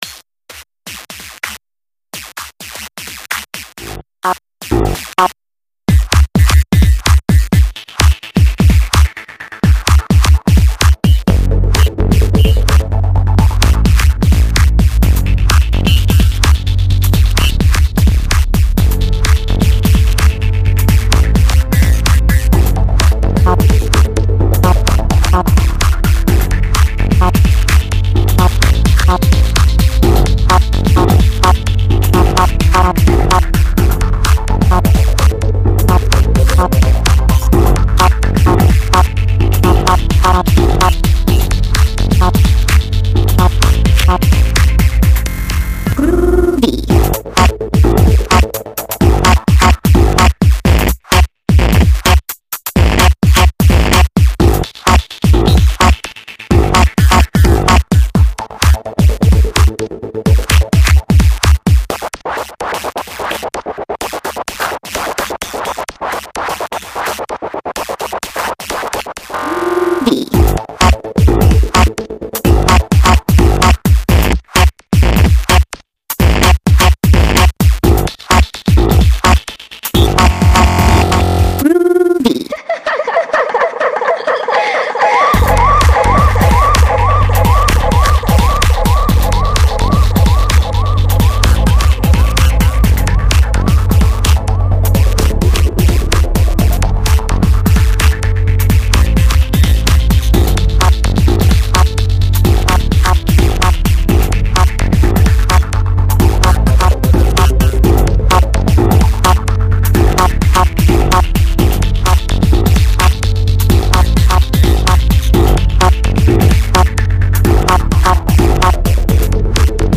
a minimal techno/ 2step track, all by me